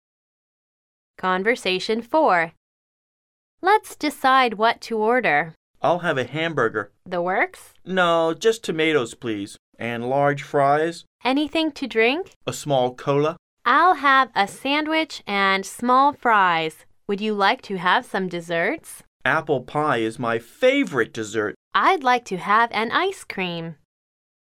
旅游英语口语情景对话大全：快餐自助 4(mp3下载+lrc)
Conversation 4